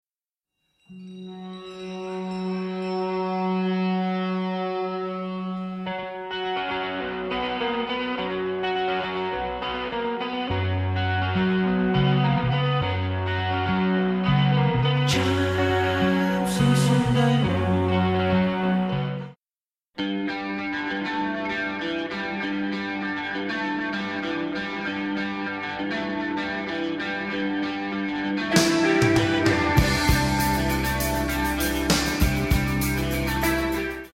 similarly slithering guitars